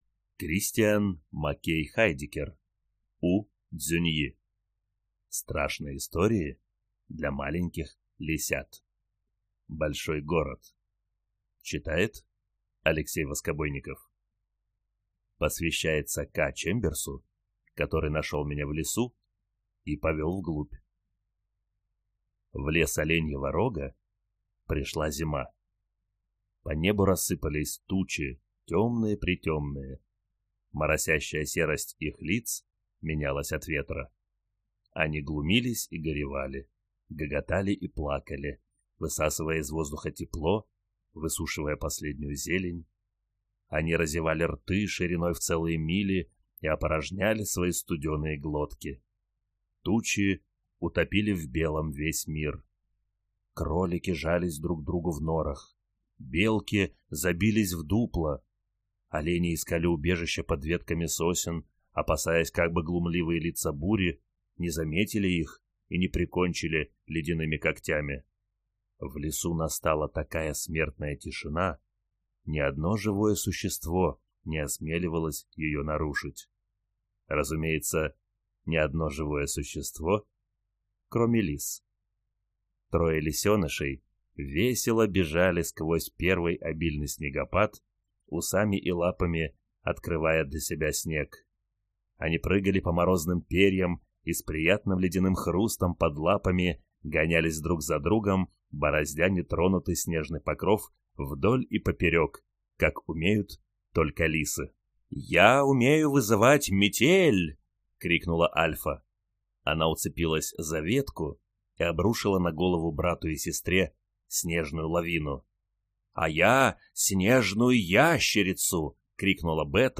Аудиокнига Страшные истории для маленьких лисят. Большой город | Библиотека аудиокниг